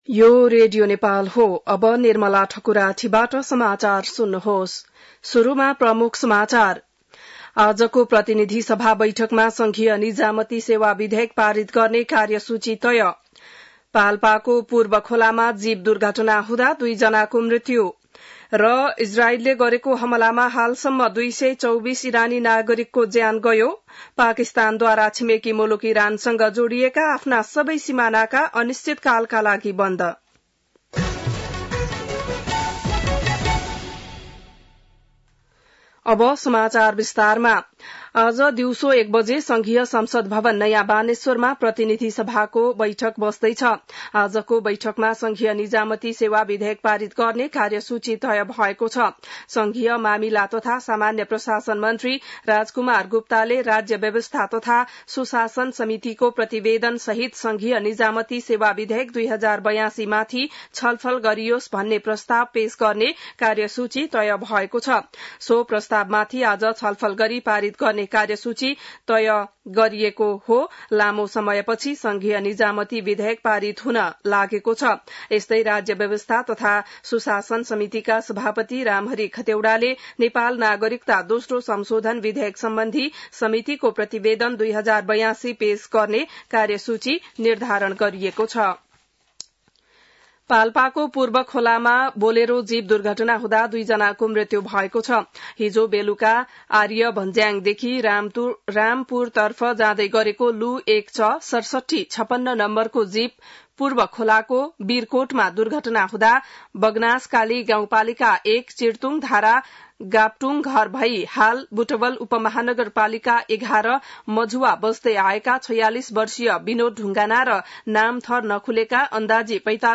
बिहान ९ बजेको नेपाली समाचार : ३ असार , २०८२